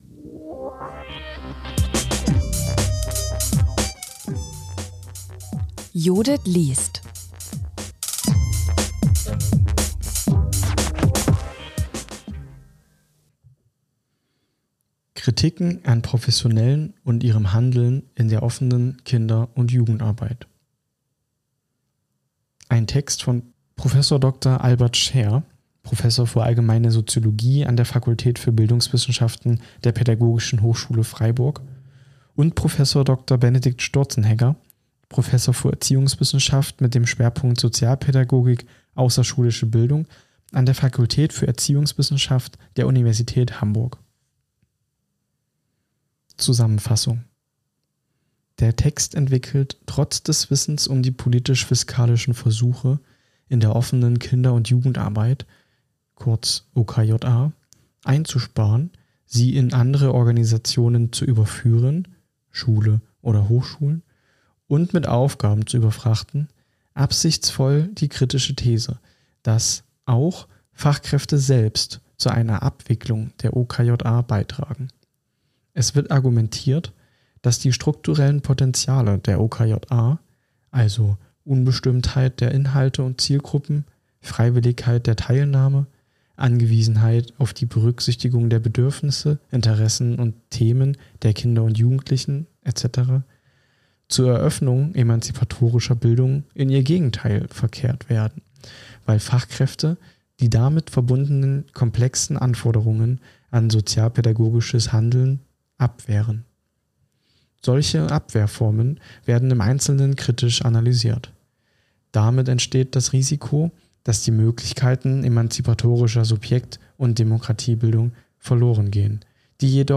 Ein eingelesener Text